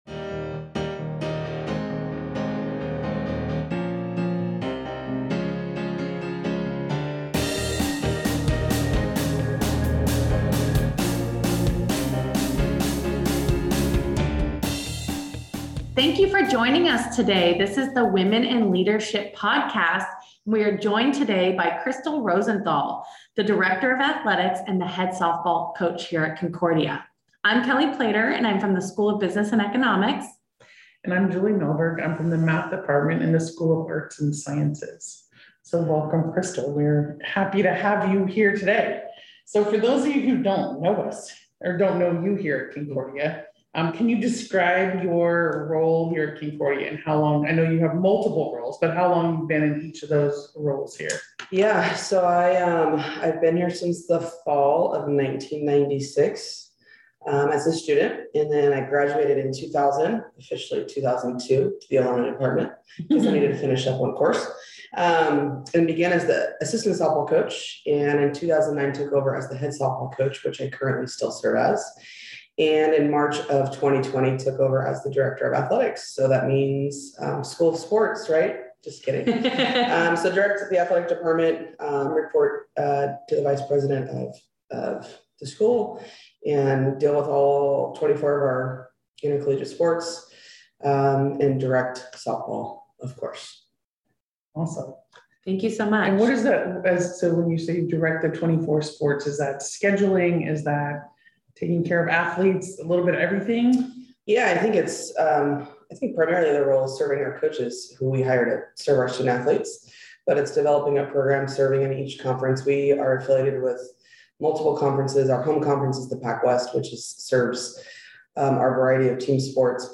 AWiL Interview